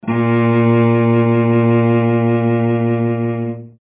cello